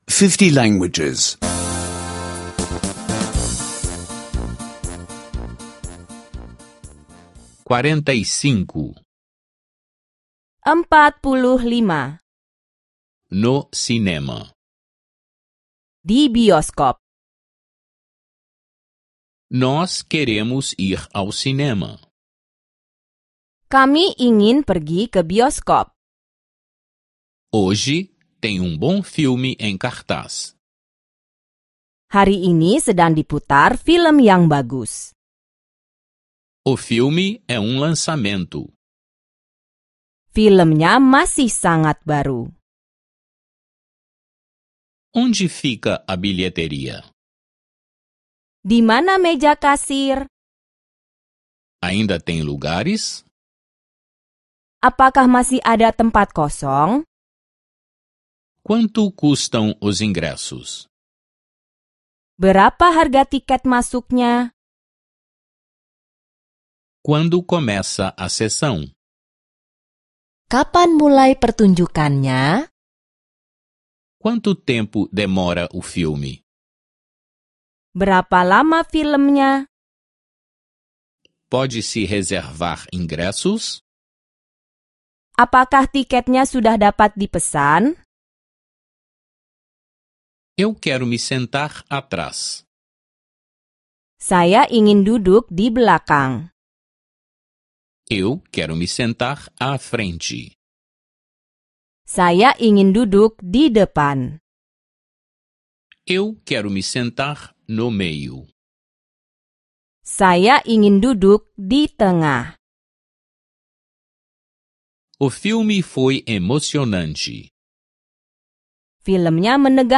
Aulas de indonésio em áudio — escute online